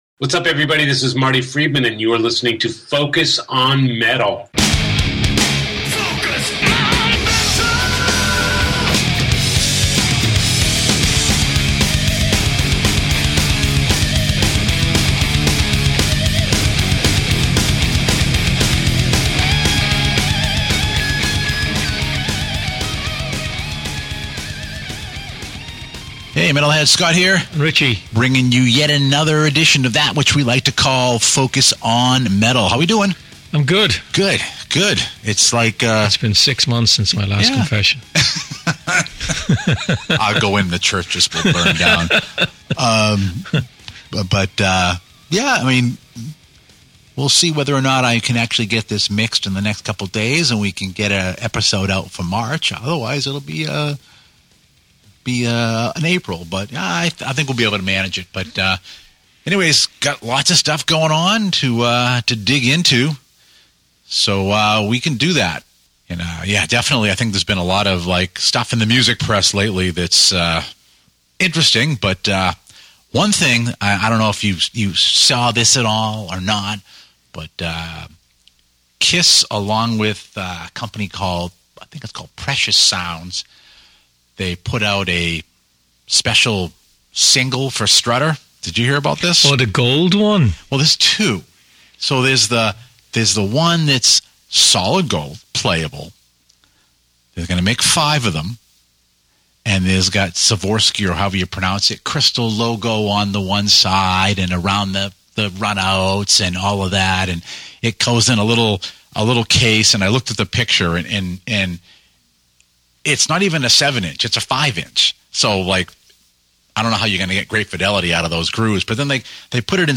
Since 2010 Focus on Metal has been a premier global metal crazed netcast featuring dedicated and knowledgeable fans with years of radio experience. This show features interviews with metal's most important personalities, the latest metal headlines, captivating topics, great music, reviews of the latest releases and plenty of segments from contributing metalheads.